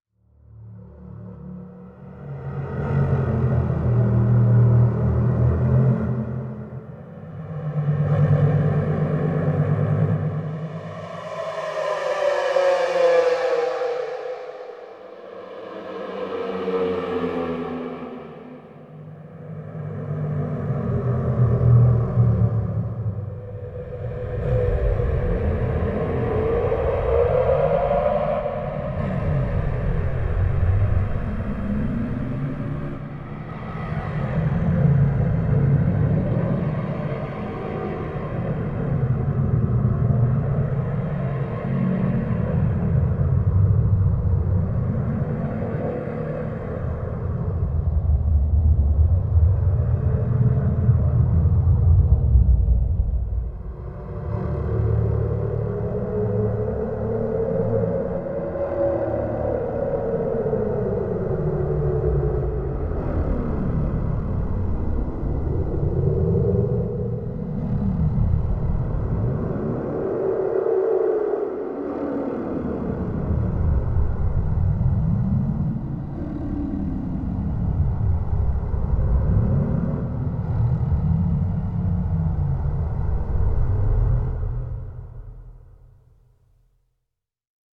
Atmosphere TFH03_14.wav